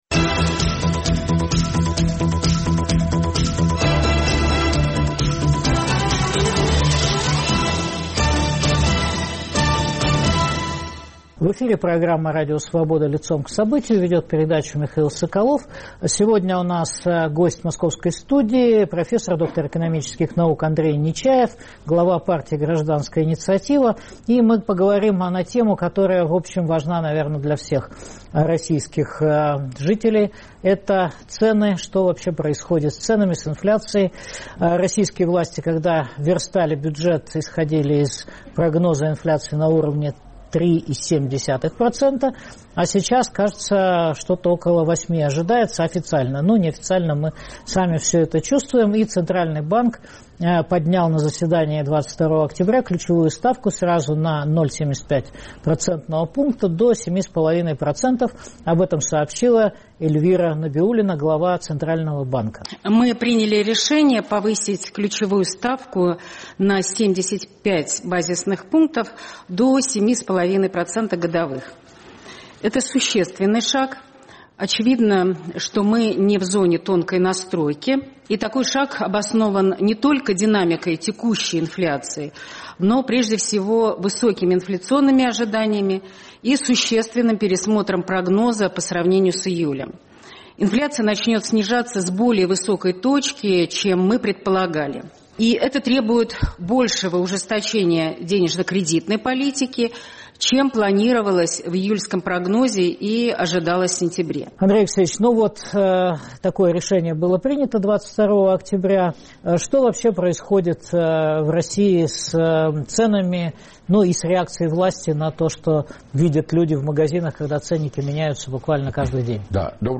В чем главная причина нового взлета цен в России? Гость программы доктор экономических наук Андрей Нечаев, глава партии "Гражданская инициатива".